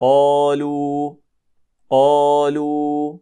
e ـــ Att uttala det viskande (Hams — الهَمس)
قَالُوٓاْ﴿           Det ska uttalas på följande sätt: